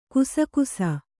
♪ kusakusa